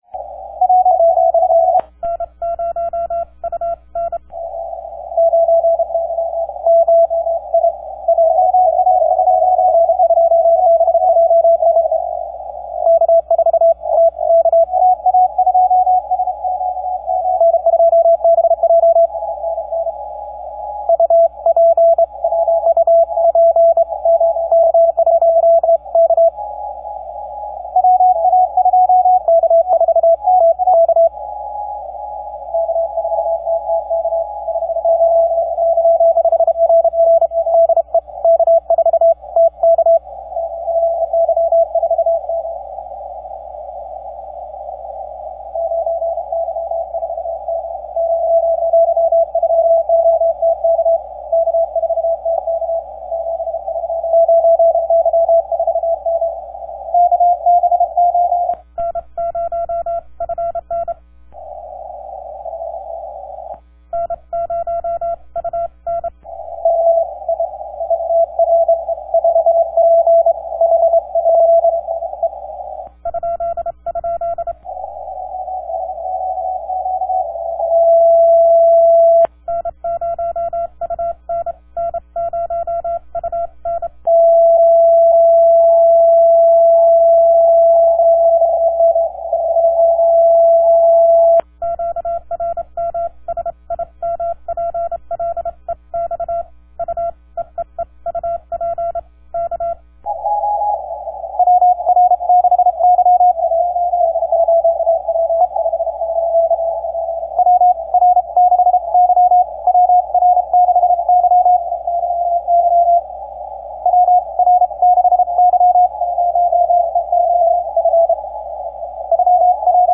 What a mess!
And just for your listening pleasure here’s a short recording of that non-stop 40 Meter simplex craziness this evening, lol.